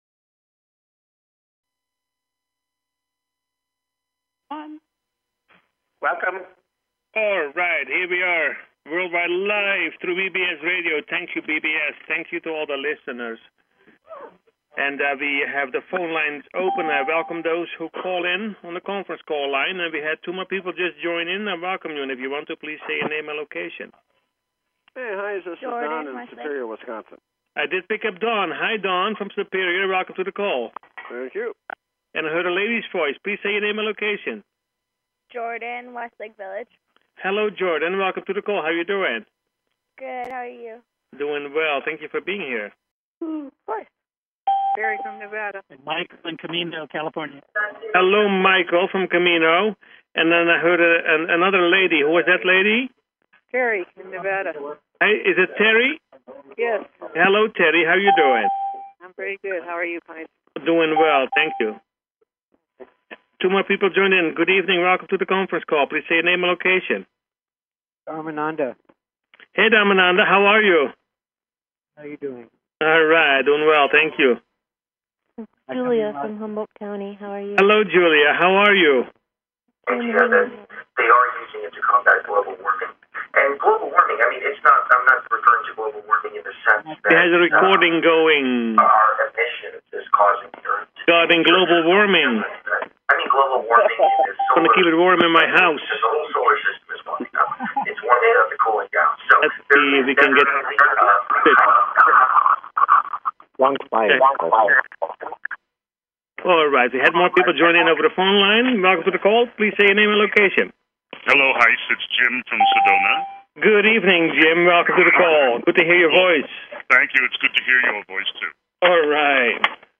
Talk Show Episode, Audio Podcast, You_Got_Questions_We_Got_Answers and Courtesy of BBS Radio on , show guests , about , categorized as